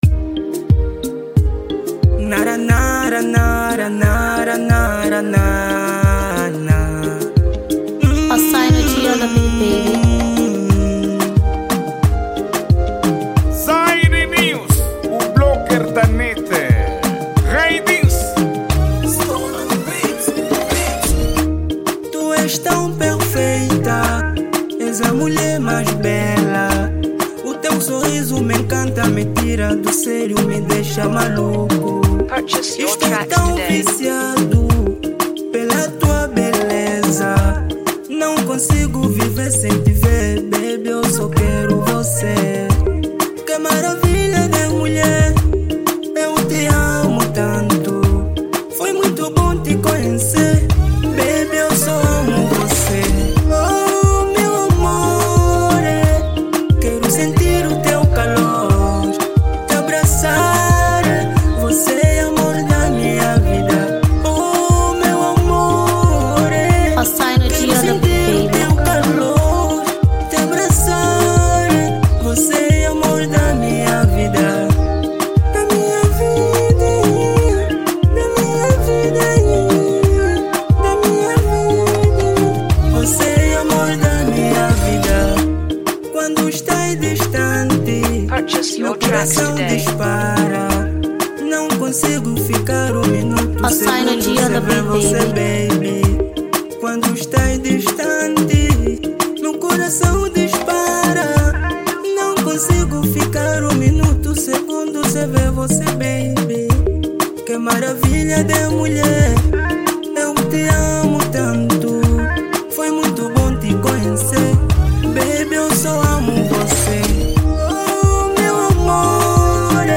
estilo de Zouk
Gênero:Zouk